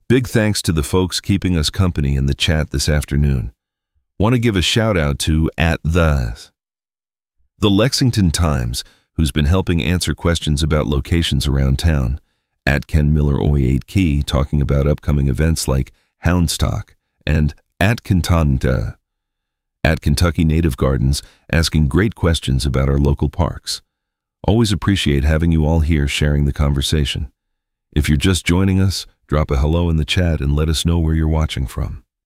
This transcript is from a recent on-air segment.
Voice synthesis via ElevenLabs; script via Claude.